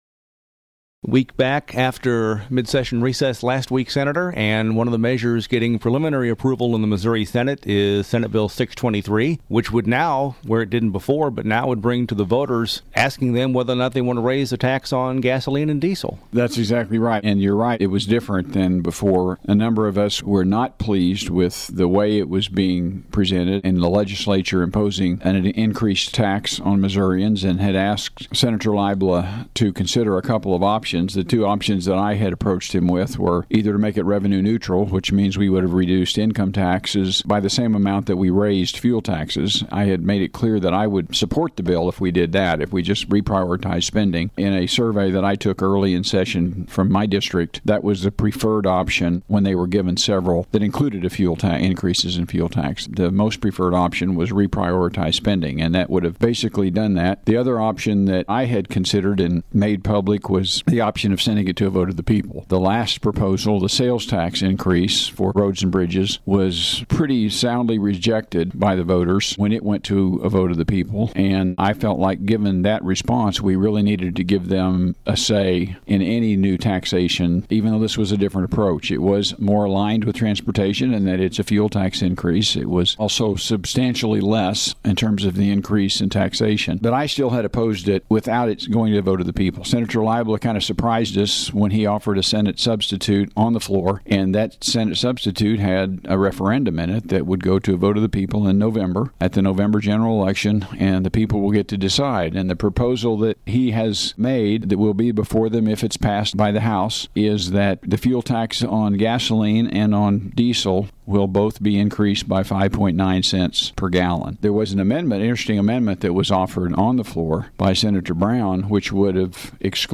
The audio below is a full-length interview with Sen. Emery — also available as a podcast — for the week of March 28, 2016.